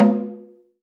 AFRO.TAMB1-S.WAV